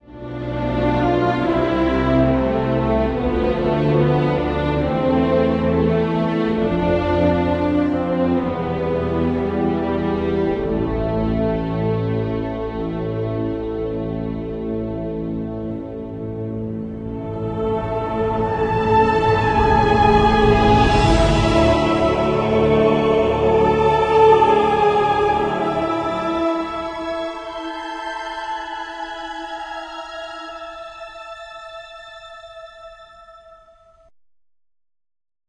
An instrumental verison